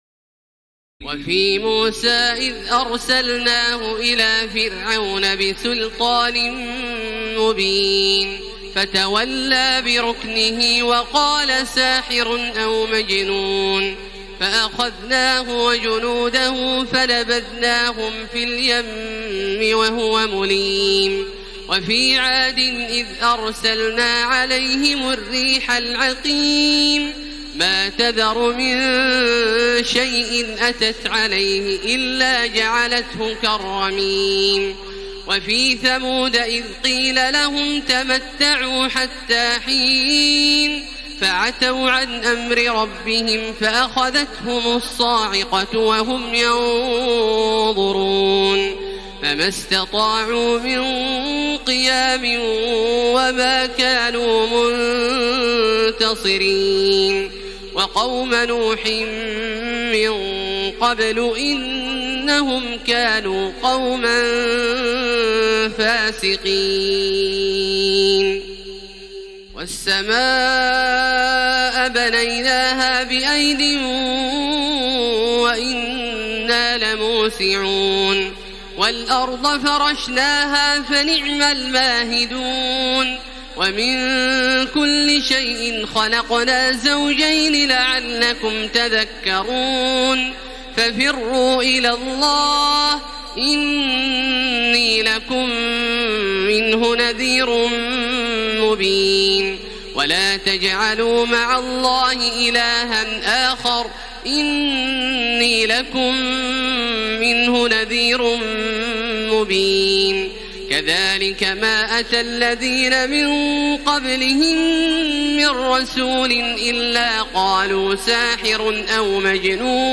تراويح ليلة 26 رمضان 1433هـ من سور الذاريات(38-60) و الطور و النجم و القمر Taraweeh 26 st night Ramadan 1433H from Surah Adh-Dhaariyat and At-Tur and An-Najm and Al-Qamar > تراويح الحرم المكي عام 1433 🕋 > التراويح - تلاوات الحرمين